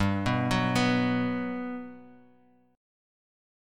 GM7 chord